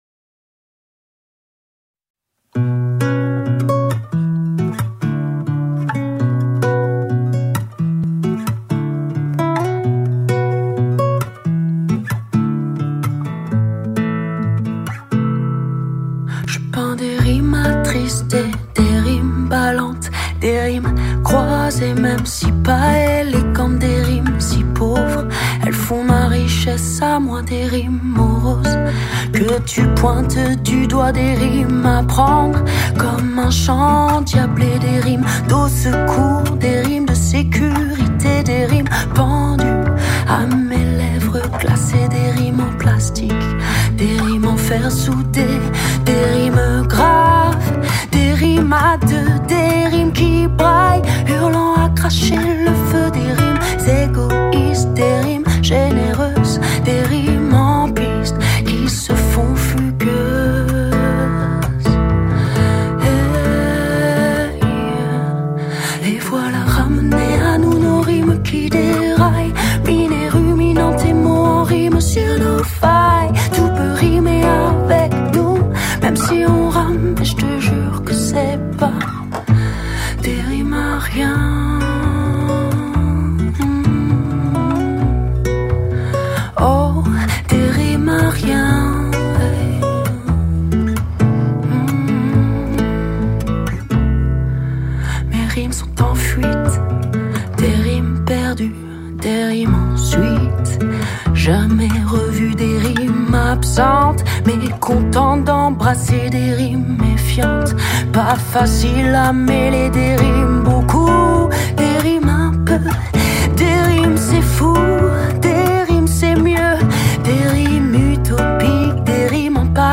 musiques et mots, comme son nom l'indique